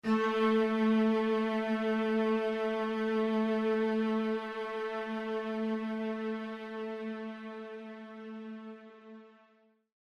Нота: Ля первой октавы (A4) – 440.00 Гц
Note7_A4.mp3